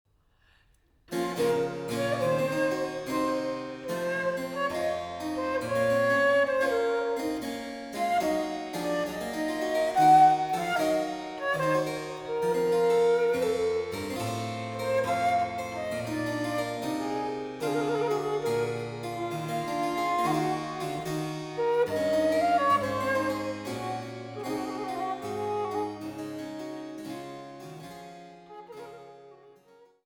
Menuet